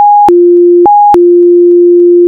FSK69.wav